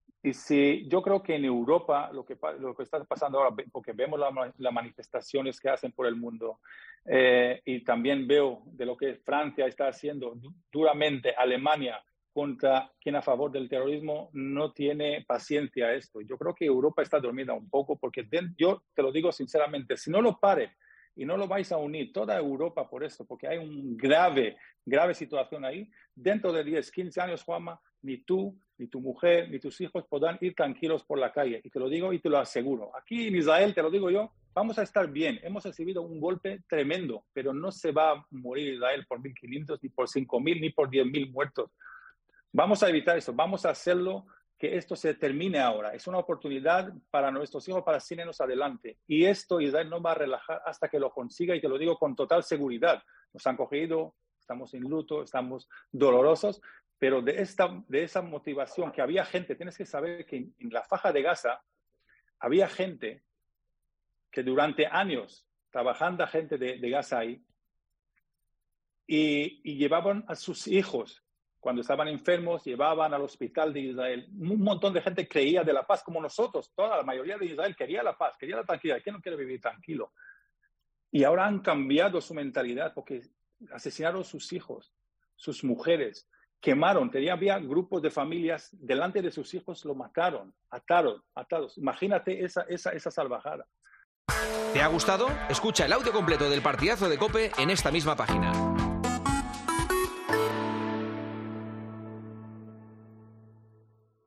El portero hebreo fue muy contundente en El Partidazo de COPE a la hora de hablar del conflicto en Gaza